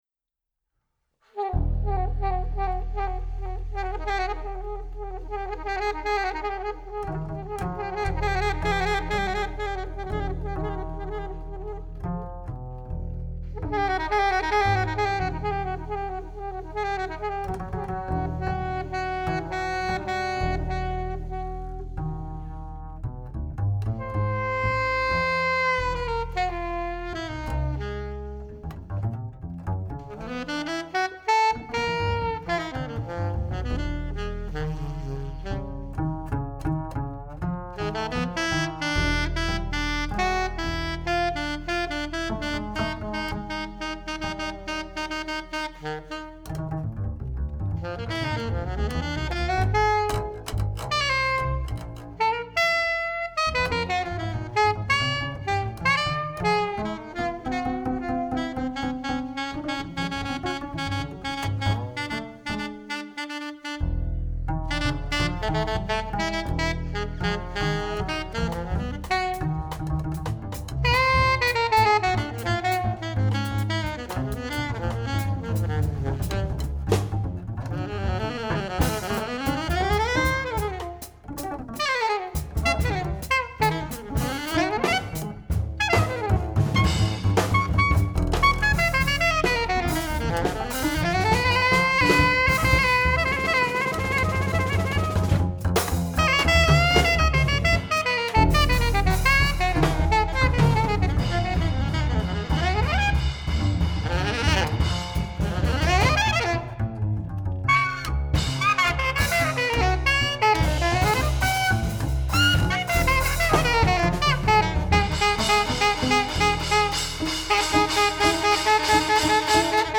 trumpet
alto saxophone
trombone
double bass
drums